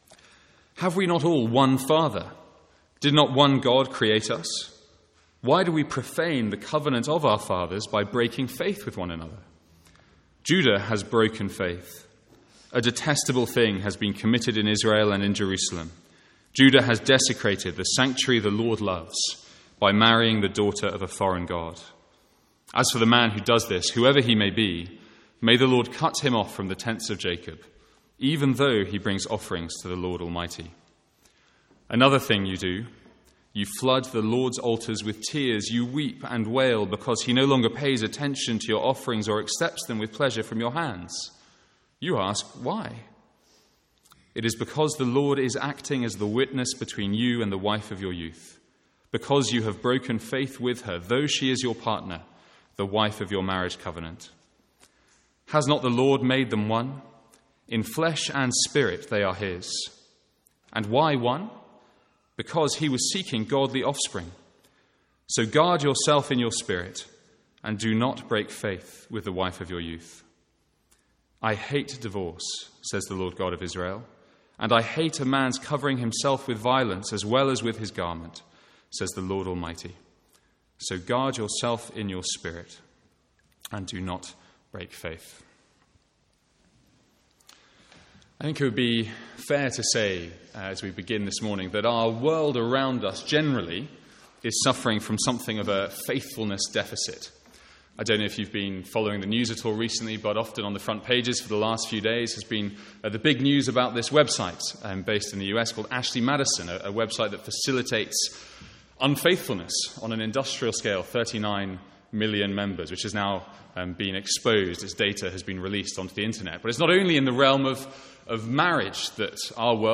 Sermons | St Andrews Free Church
From the Sunday morning series in Malachi.